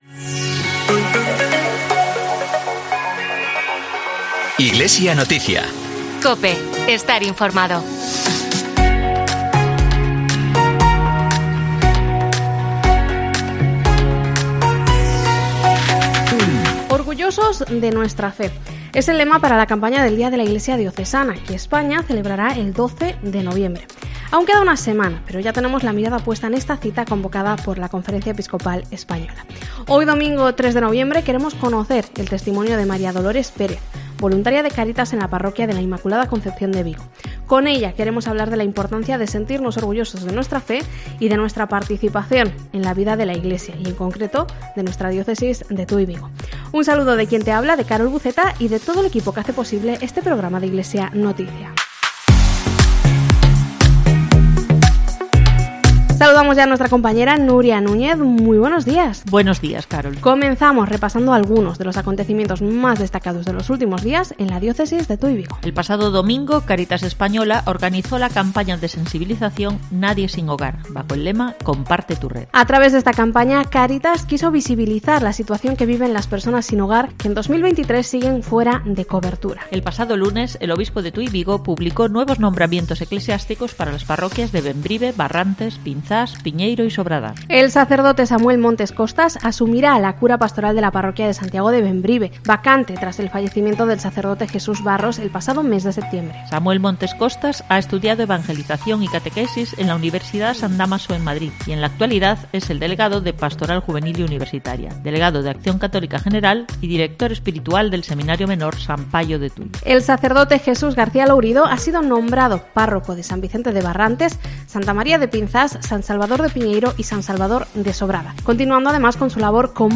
AUDIO: Informativo Diocesano